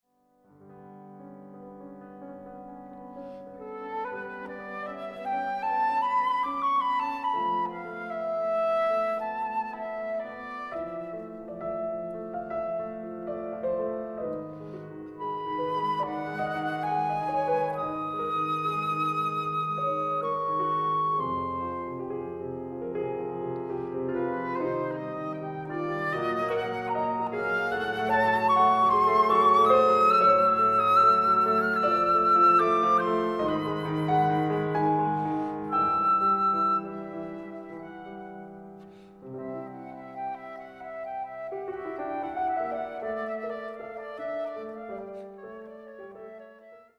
Leo Smit – Sonata for flute and piano 2. Lento
fluit